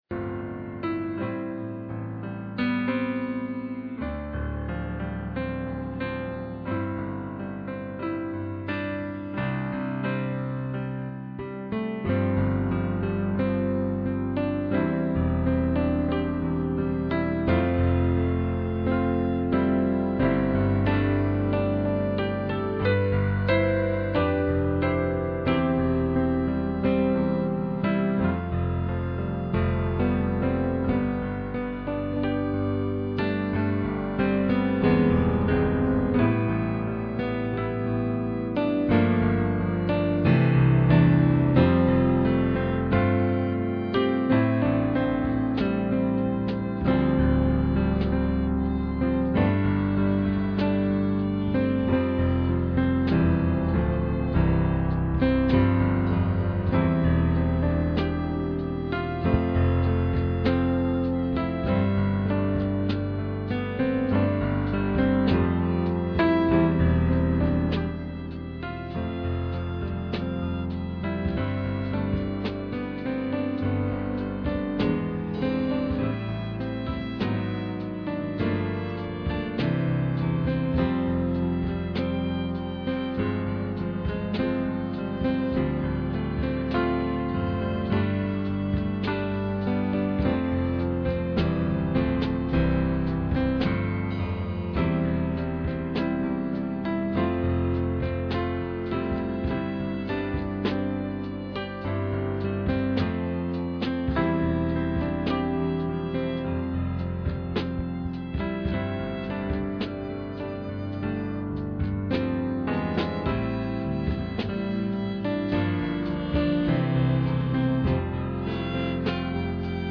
1 Thessalonians 5:1-9 Service Type: Sunday Morning %todo_render% « Ruins Revelation Chapter 8